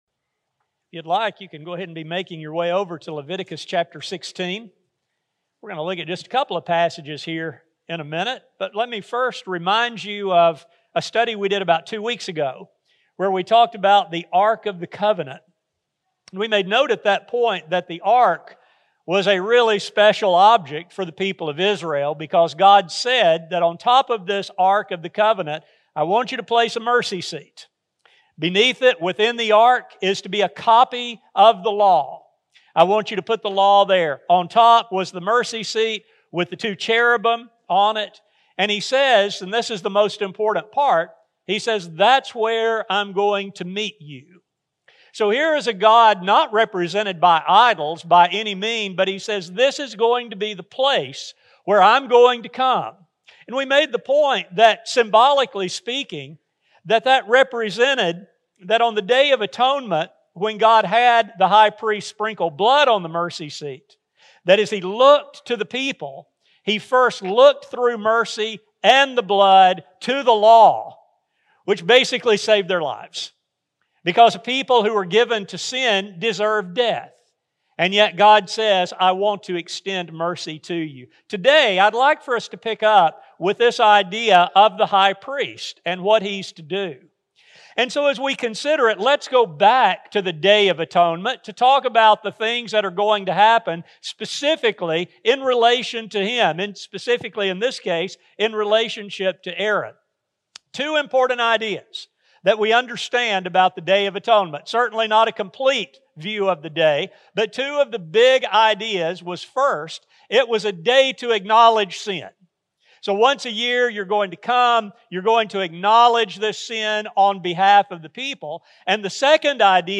These Old Testament shadows provide a glimpse of the reality now known through Jesus Christ. This sermon explores these connections and ultimately demonstrates that He is the hope of the ages and the way we return to fellowship with God. A sermon recording